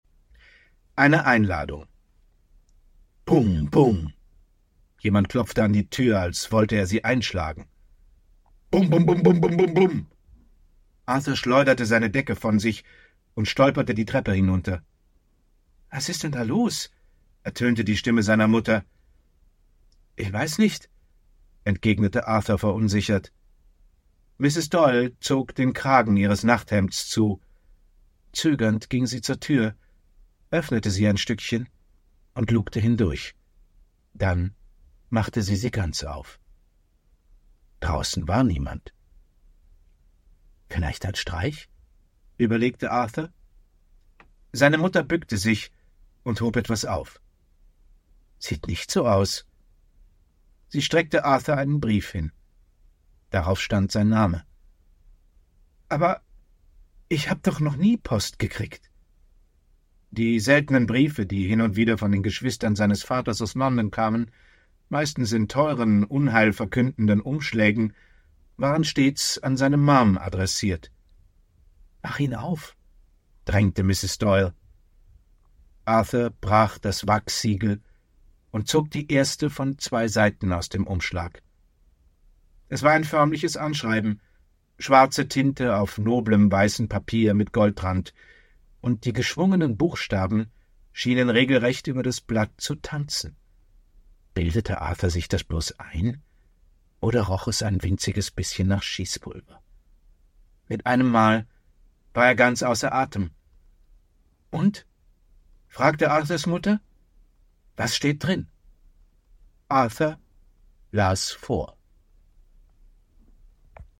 Ungekürzte Lesung mit Johann von Bülow (1 mp3-CD)
Johann von Bülow (Sprecher)